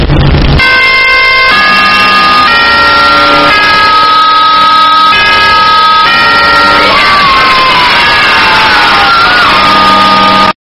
Som de FNAF 1 6AM Estourado
Categoria: Sons virais
som-de-fnaf-1-6am-estourado-pt-www_tiengdong_com.mp3